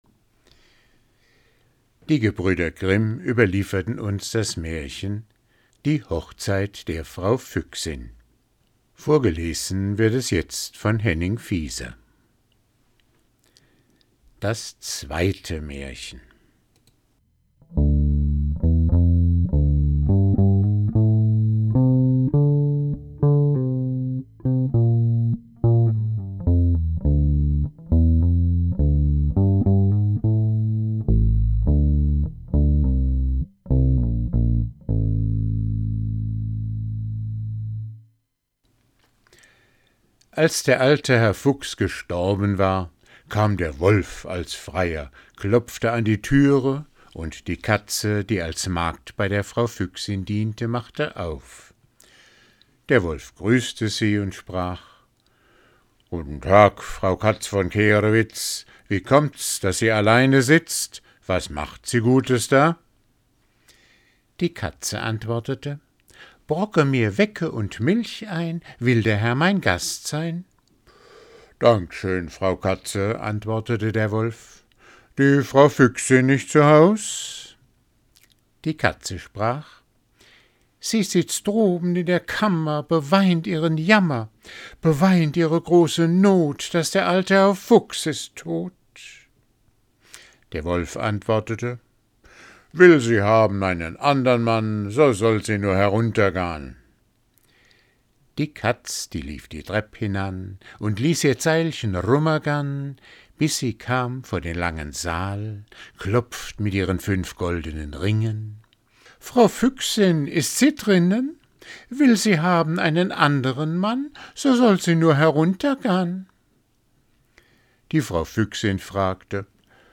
Vorgelesen